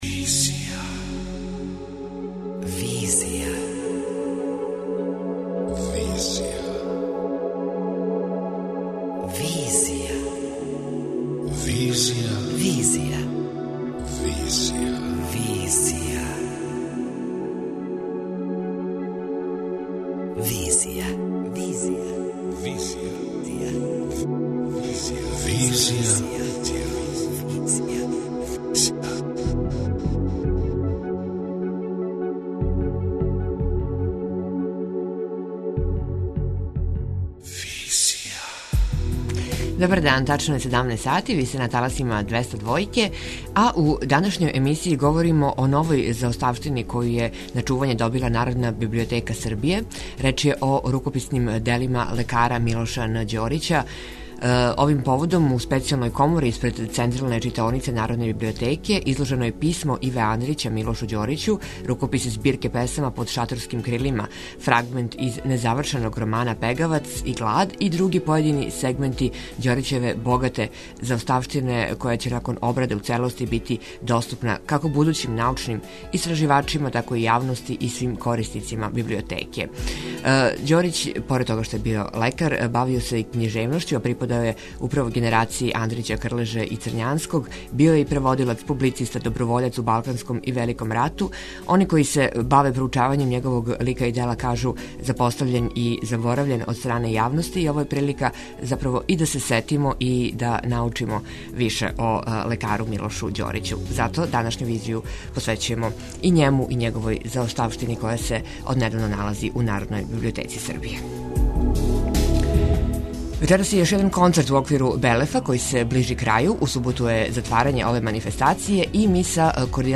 преузми : 26.88 MB Визија Autor: Београд 202 Социо-културолошки магазин, који прати савремене друштвене феномене.